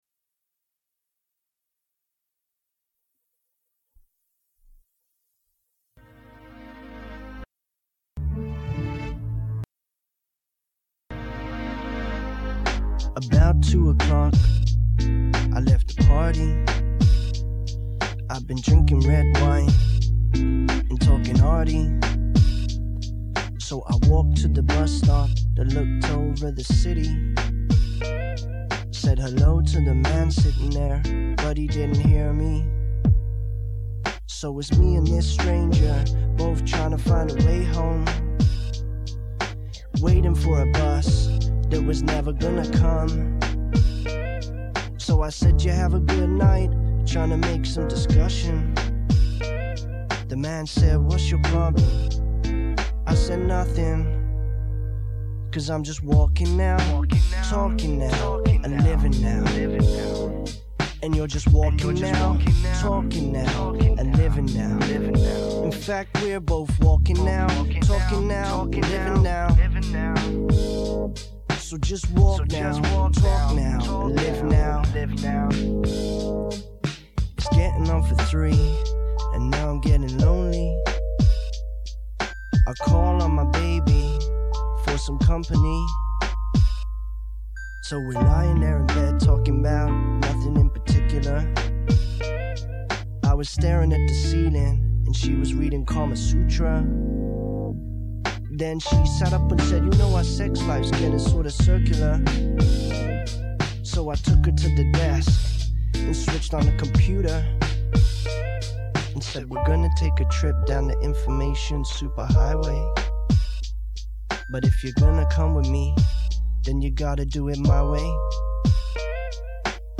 Recorded on June 11/00 from reel to reel tape